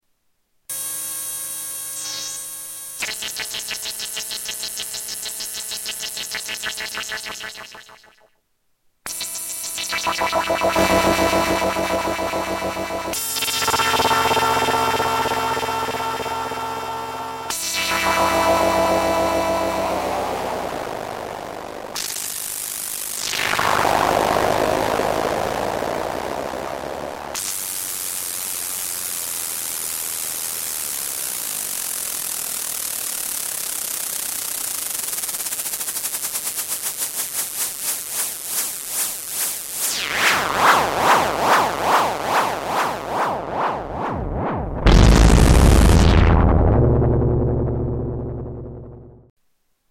Category: Sound FX   Right: Personal
Tags: Sound Effects EML ElectroComp 101 EML101 ElectroComp 101 Synth Sounds